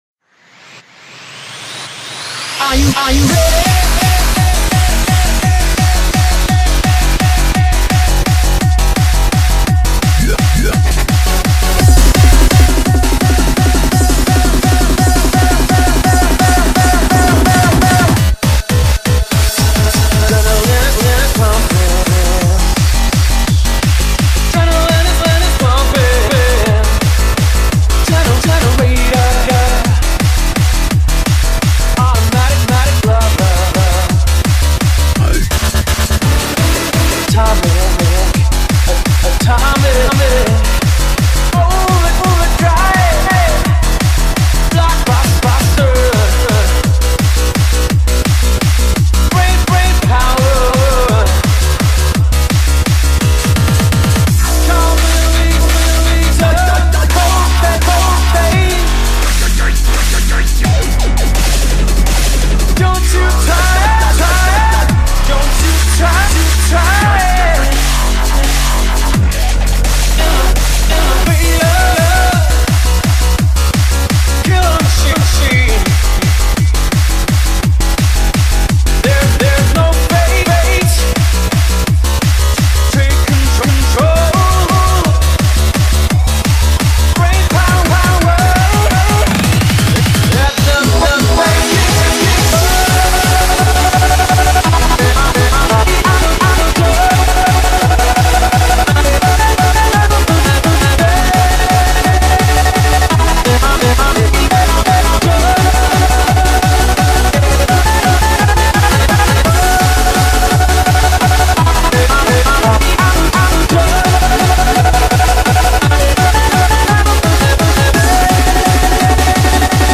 BPM170
Audio QualityPerfect (High Quality)
every other beat is repeated.